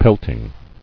[pelt·ing]